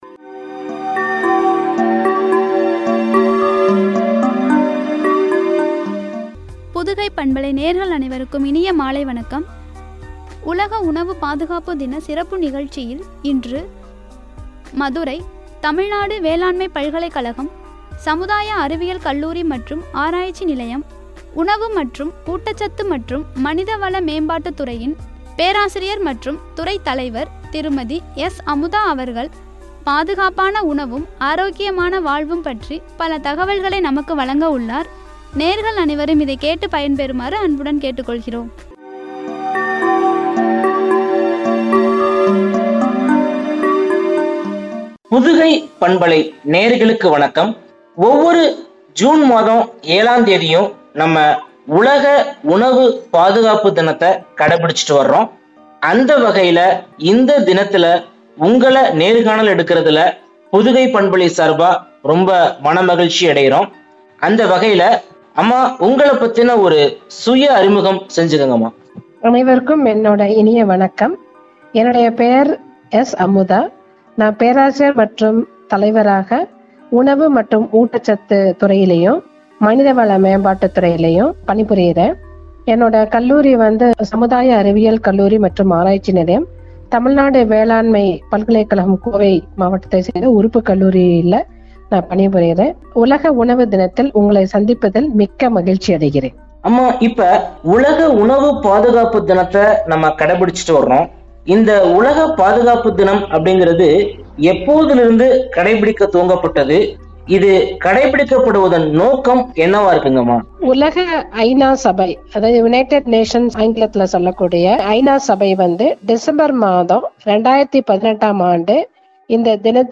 ஆரோக்கியமான வாழ்வும்” குறித்து வழங்கிய உரையாடல்.